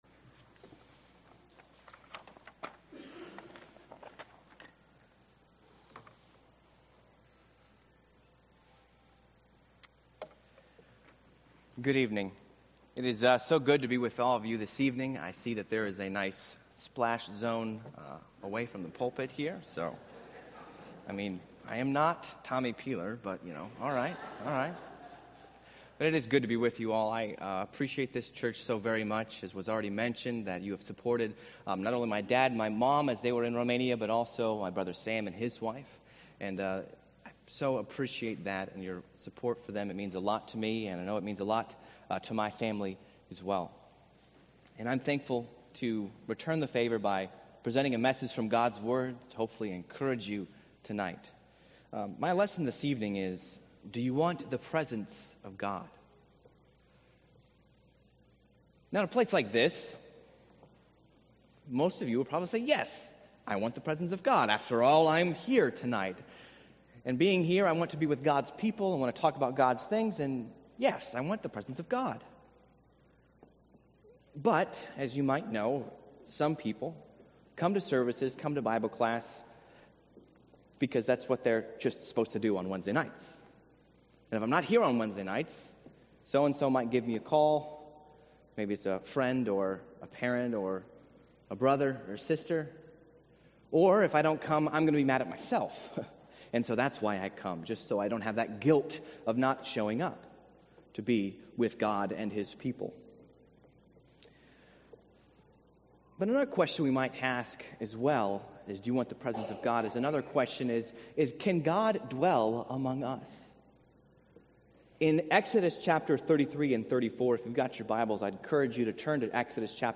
Sermons Do You Want the Presence of God?